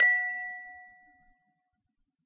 clean ding metal musicbox note sample toy sound effect free sound royalty free Music